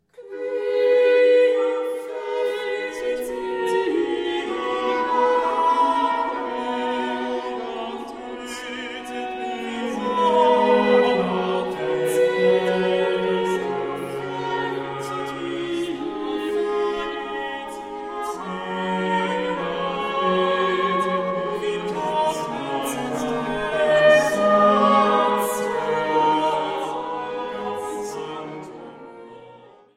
Leitung und Posaune